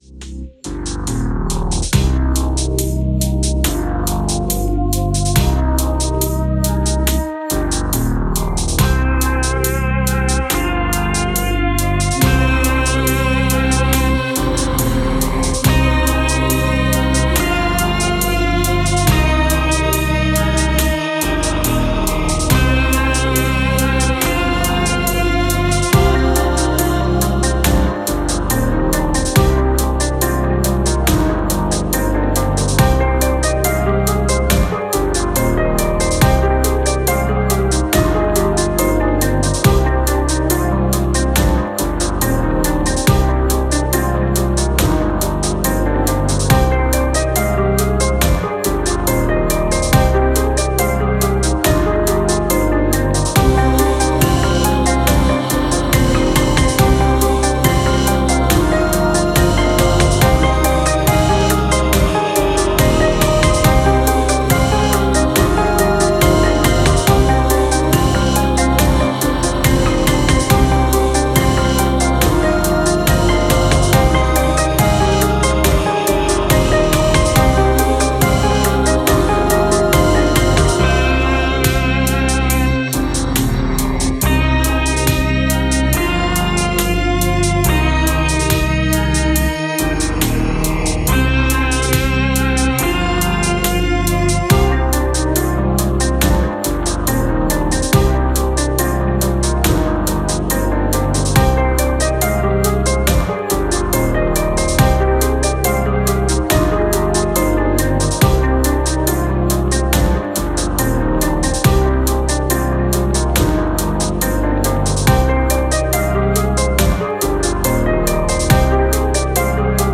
Musique Rap, trap, boombap libre de droit pour vos projets.